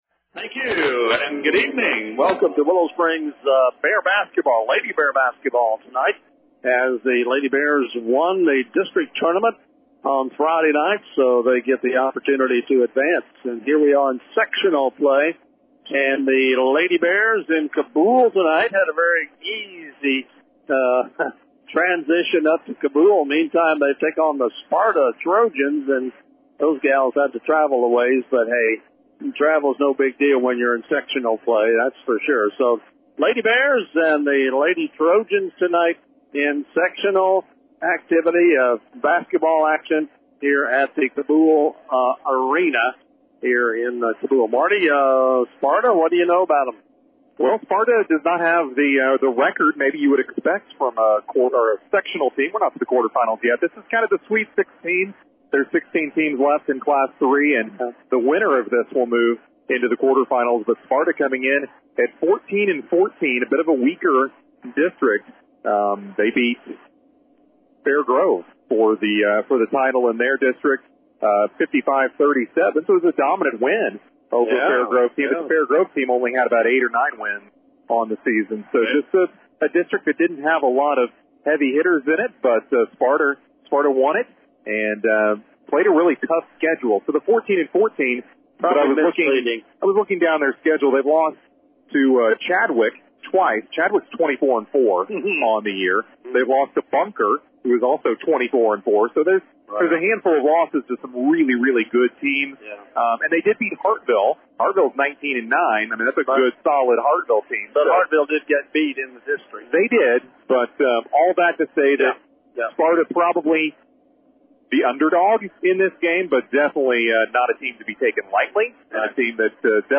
Cabool, MO. – The Willow Springs Lady Bears faced off against the Sparta Trojans thie evening in a State Sectional game.
Lady-Bears-vs-Sparta-3-3-26.mp3